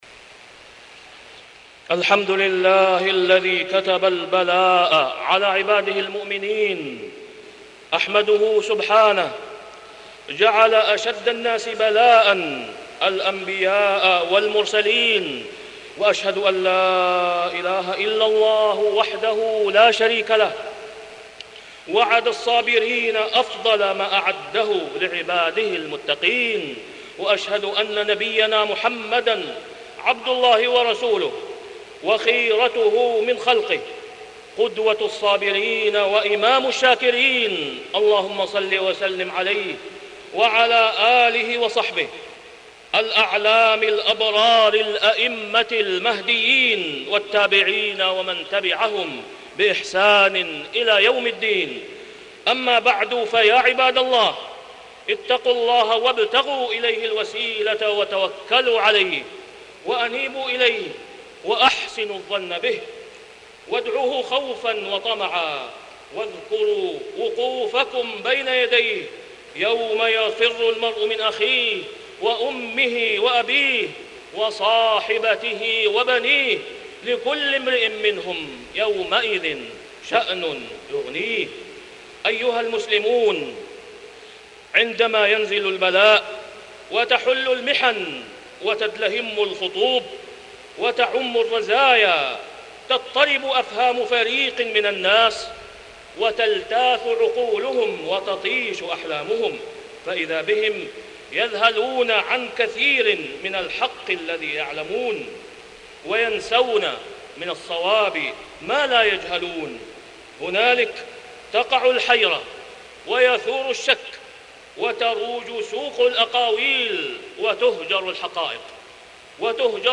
تاريخ النشر ١٦ شوال ١٤٢٦ هـ المكان: المسجد الحرام الشيخ: فضيلة الشيخ د. أسامة بن عبدالله خياط فضيلة الشيخ د. أسامة بن عبدالله خياط سنة الابتلاء